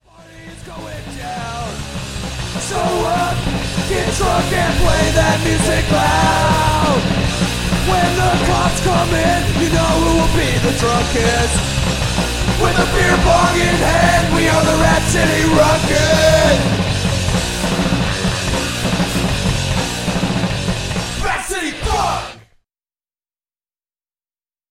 It’s full of punk rock energy and grit.